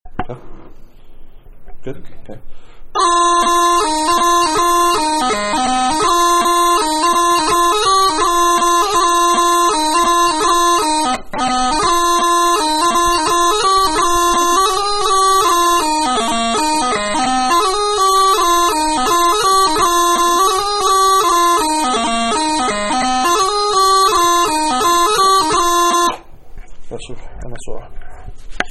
Reel 84 bpm